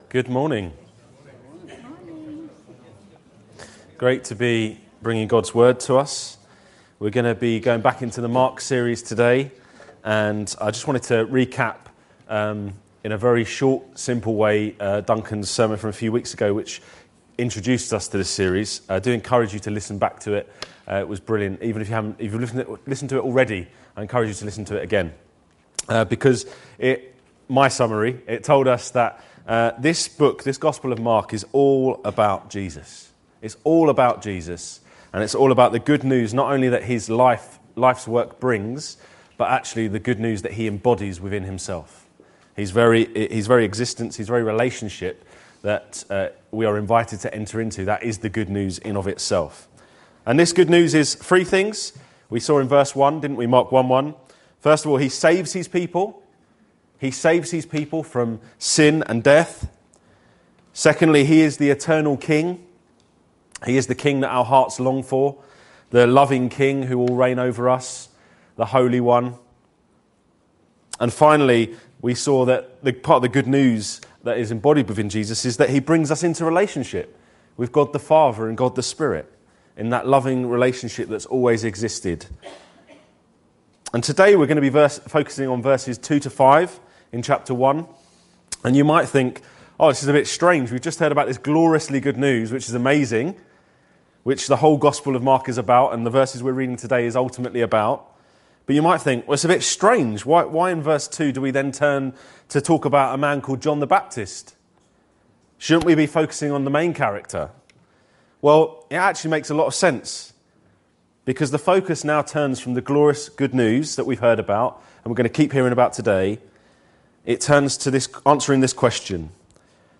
This sermon reminds us that part of Gods eternal plan for sharing the glorious good news embodied within Jesus, with people, involves using his followers as messengers.